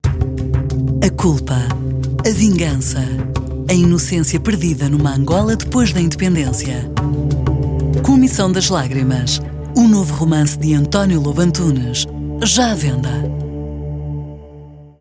品牌广告-优雅大气